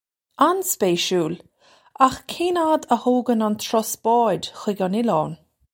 An-spay-shool! Akh kayn ad uh hoe-gun un truss baw-idge hig un illawn?
This is an approximate phonetic pronunciation of the phrase.